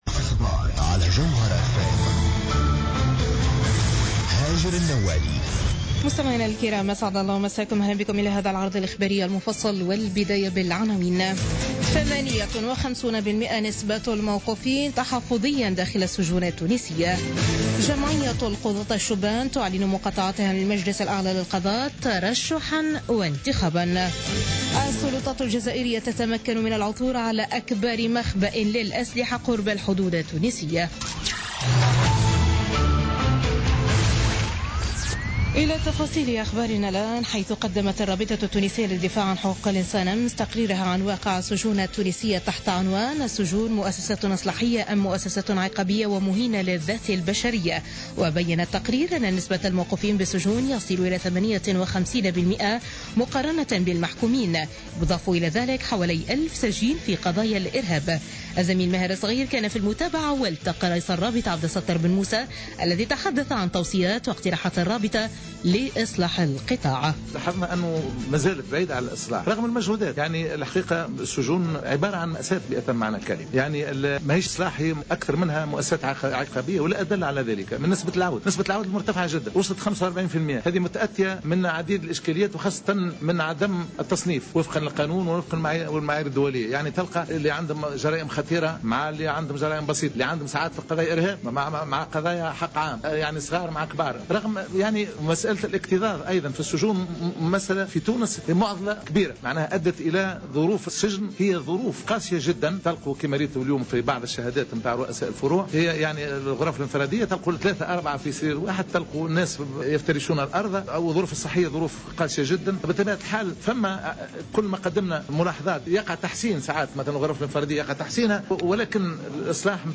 نشرة أخبار منتصف الليل ليوم الأحد 8 ماي 2016